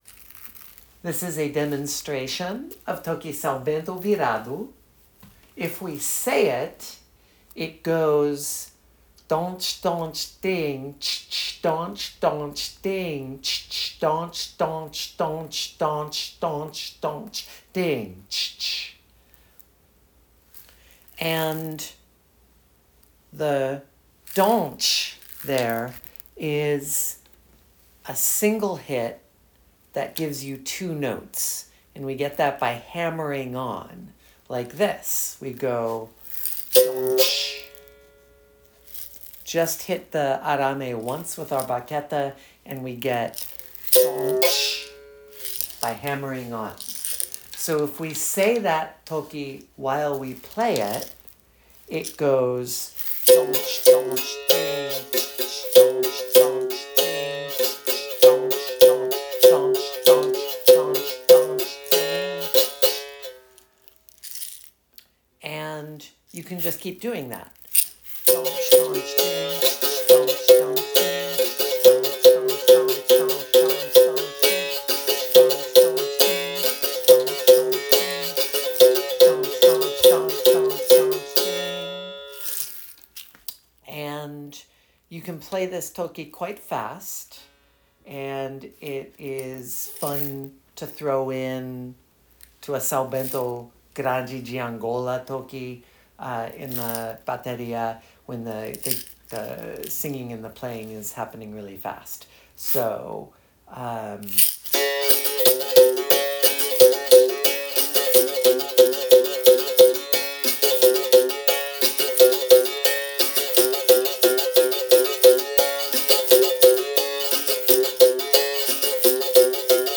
Don-sh Don-sh Ding Tch Tch
Don-sh Don-sh Don-sh Don-sh
Here is a recording of me demonstrating it (or download mp3):